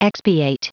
Prononciation du mot expiate en anglais (fichier audio)
Prononciation du mot : expiate